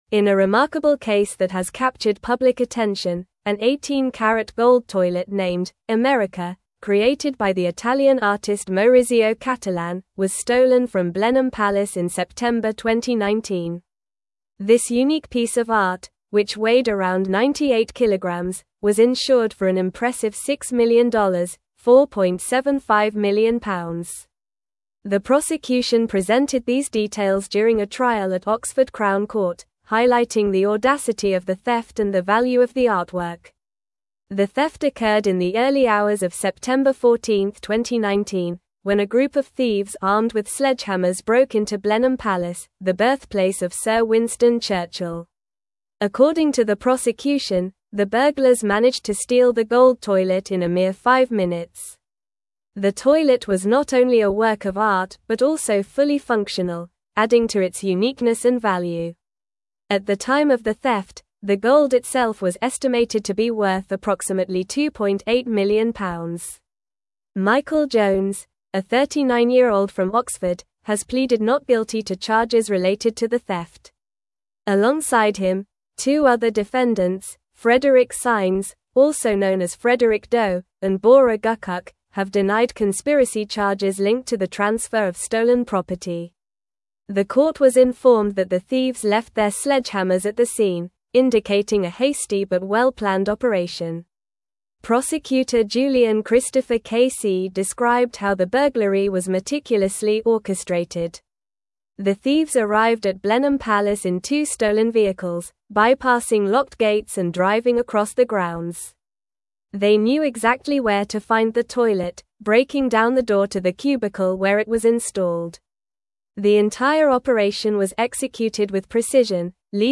Normal
English-Newsroom-Advanced-NORMAL-Reading-The-Great-Gold-Toilet-Heist-at-Blenheim-Palace.mp3